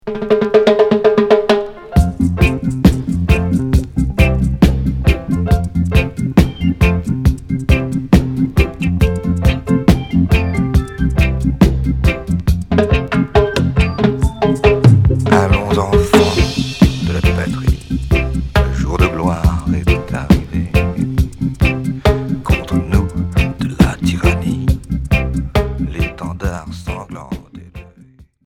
Reggae 45t en pressage allemand retour à l'accueil